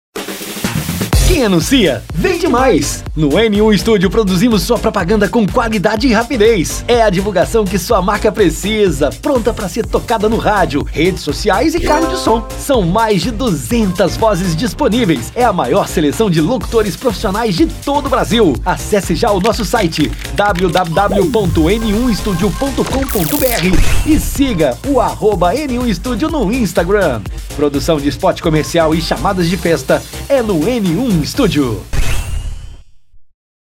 Locuções